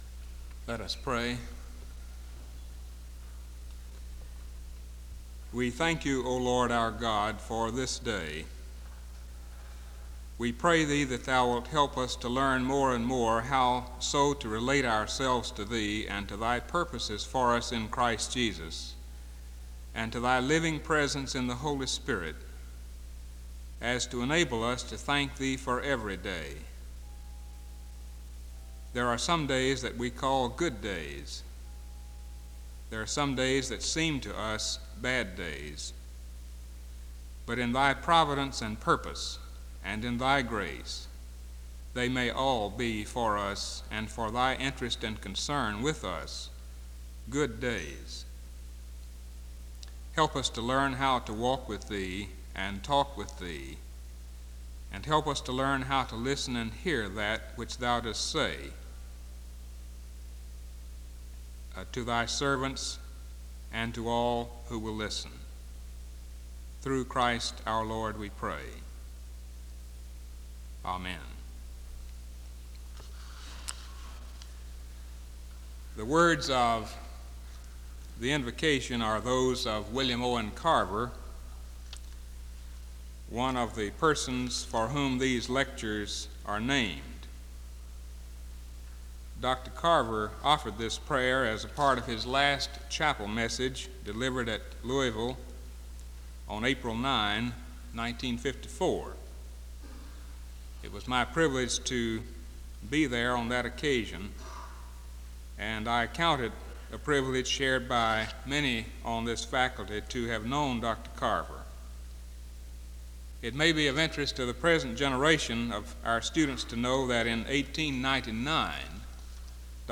SEBTS Carver-Barnes Lecture